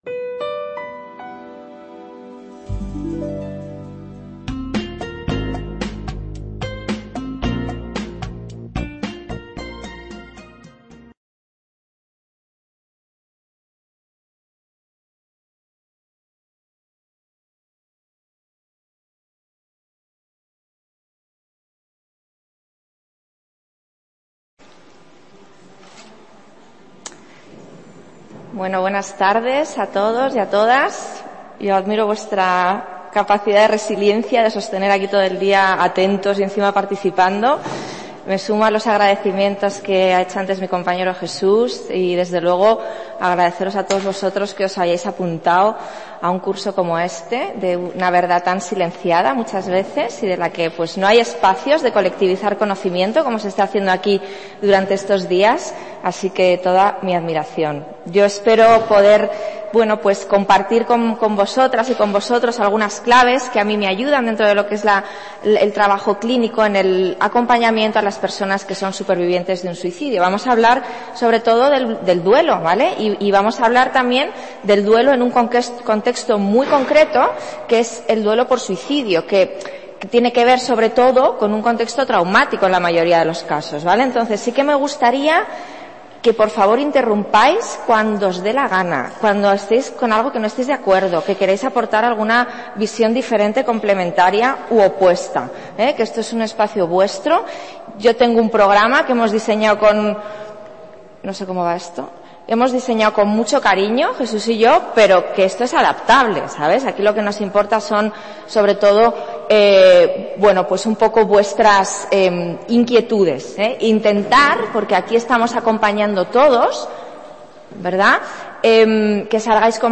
ponencia
que se celebra en Alcalá la Real (5 a 7 de julio de 2021) en los Cursos de Verano de la UNED.